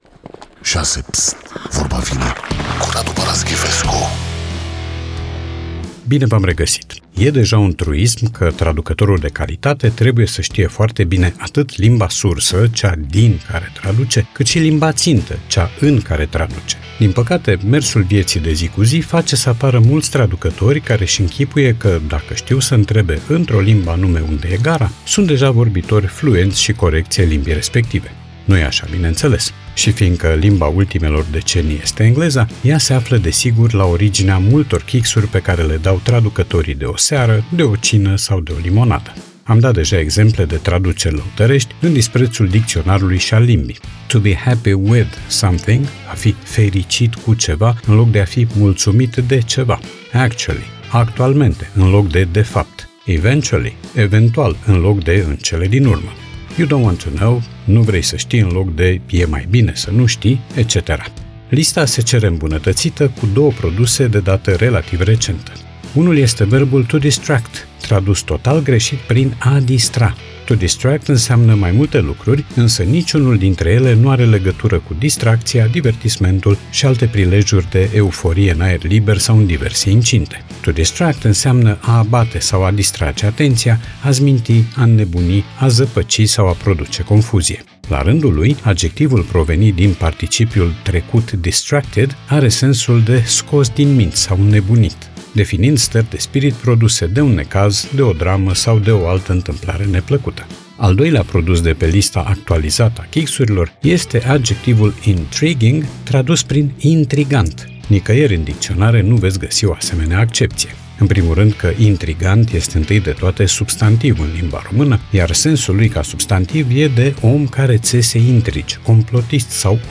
Podcast 2 septembrie 2025 Vezi podcast Vorba vine, cu Radu Paraschivescu Radu Paraschivescu iti prezinta "Vorba vine", la Rock FM.